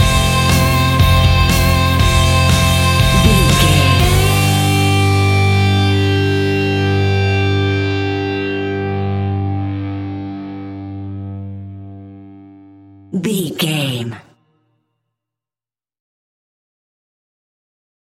Epic / Action
Fast paced
Aeolian/Minor
hard rock
instrumentals
Rock Bass
heavy drums
distorted guitars
hammond organ